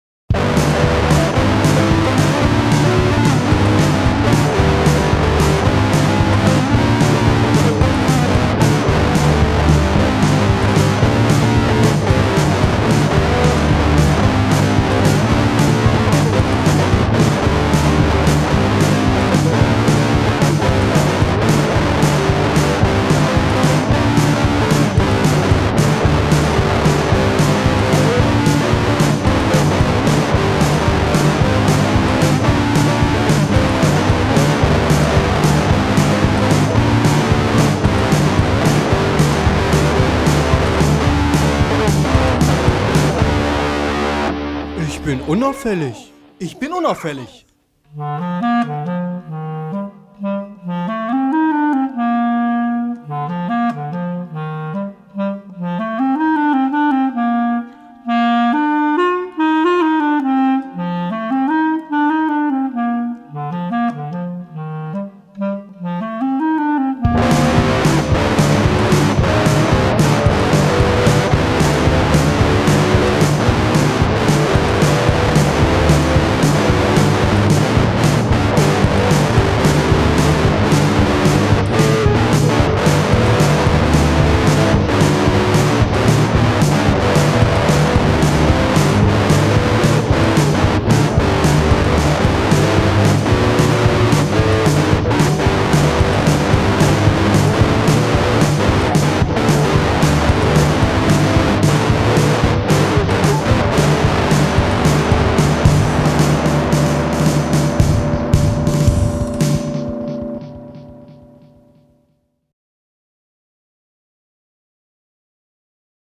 funny punky track recorded in a coffee break